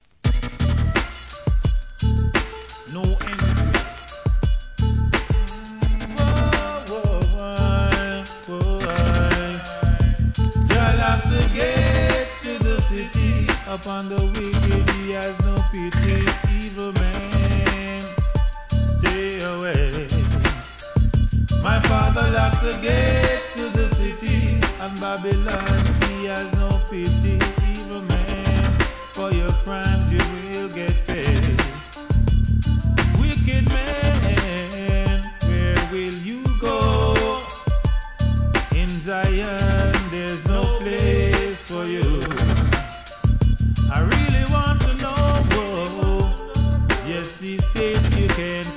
REGGAE
HIP HOP調RHYTHMでの'00sリリース!!